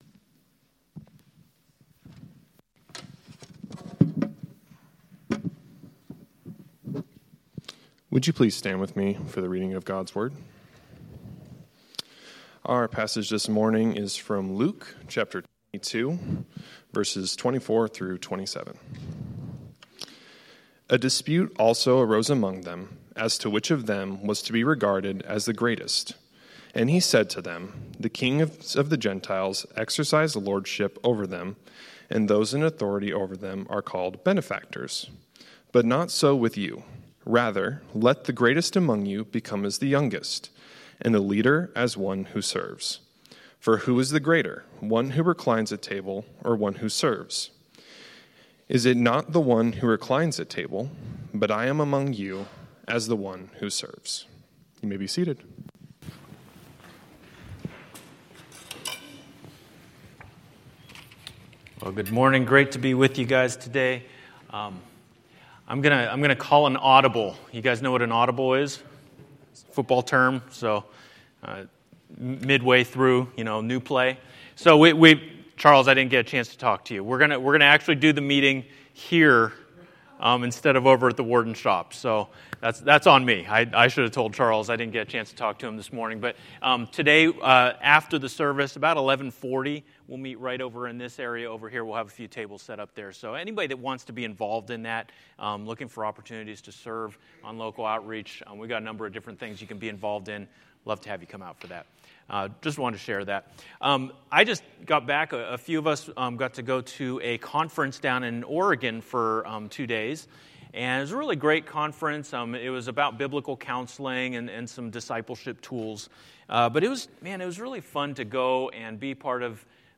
A message from the series "The Arrival Of The King."